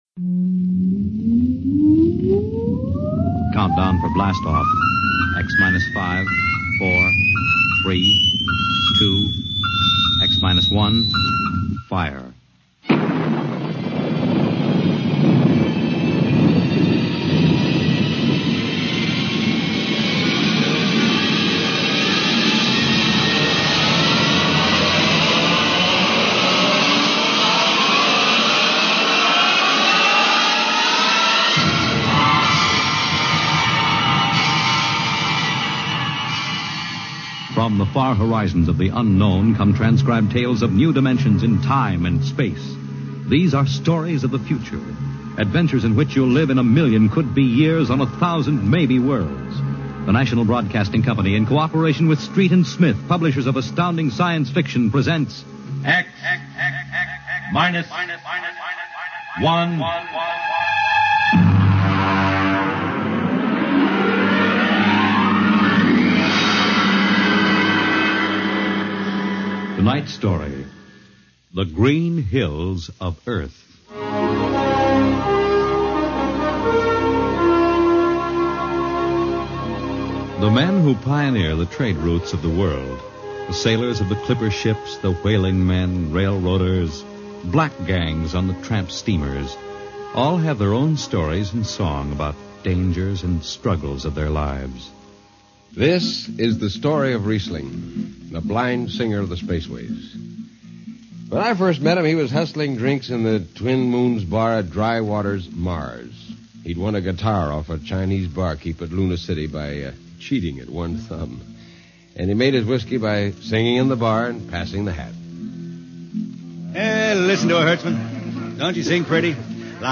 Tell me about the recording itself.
X Minus One was an early radio show that focused on Science Fiction stories. They purchased stories from authors who appeared in the pages of Galaxy Magazine and adapted them for the radio.